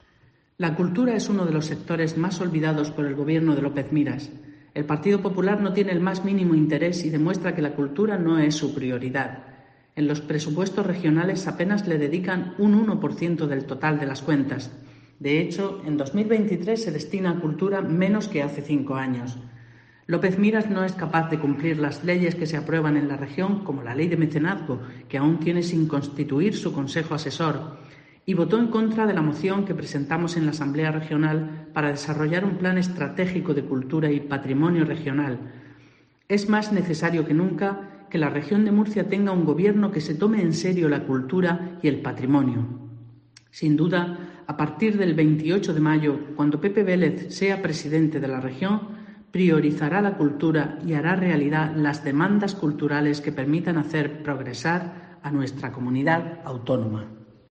Magdalena Sánchez, diputada regional del PSRM-PSOE